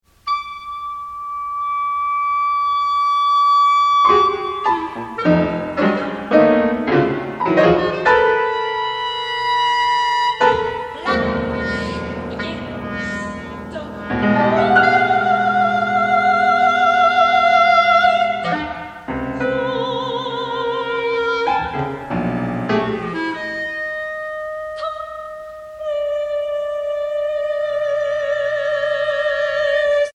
THREE PIECES FOR VOICE, CLARINET AND